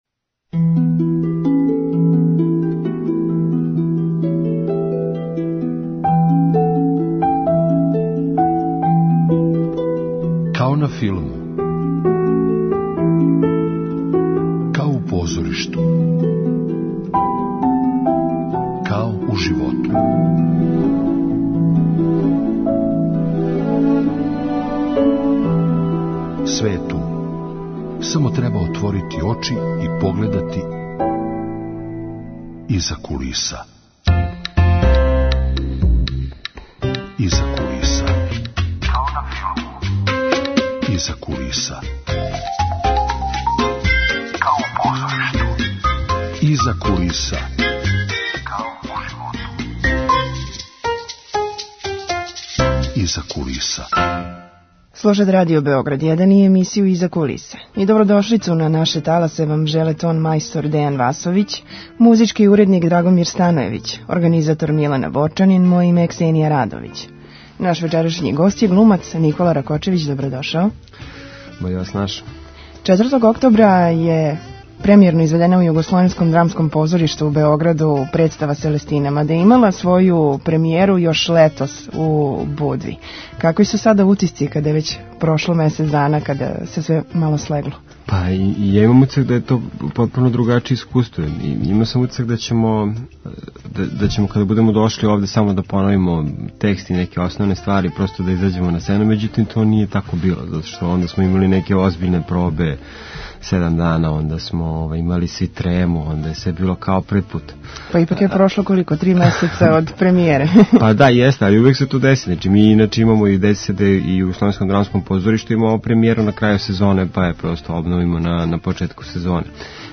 Гост: глумац Никола Ракочевић.